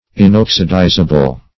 Search Result for " inoxidizable" : The Collaborative International Dictionary of English v.0.48: Inoxidizable \In*ox"idi`za*ble\, a. (Chem.) Incapable of being oxidized; as, gold and platinum are inoxidizable in the air.